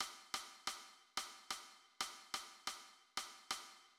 The son clave rhythm
Both patterns shown in simple meter (duple-pulse) and compound meter (triple-pulse) structures
Son_Clave_Pattern_triple.mid.mp3